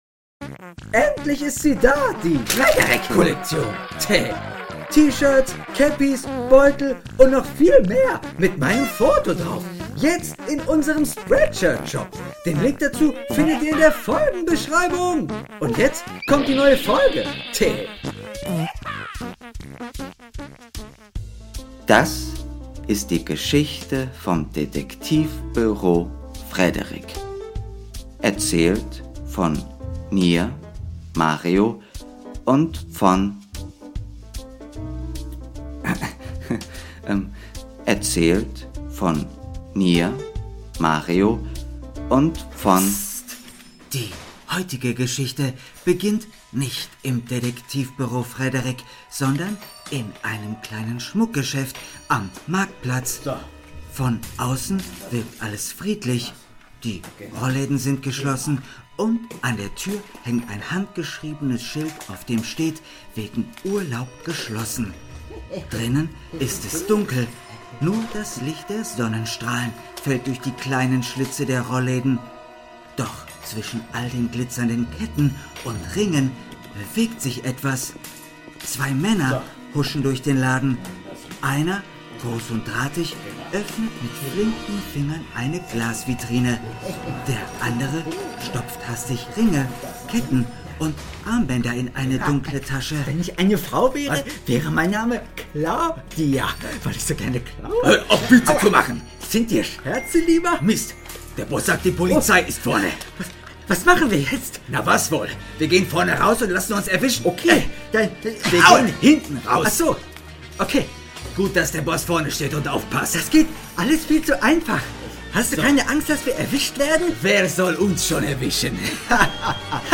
#75 - Der heiße Draht ~ Kinderhörspiel - Das Detektivbüro Frederick (Der Kinder-Podcast mit Geschichten für Kinder) Podcast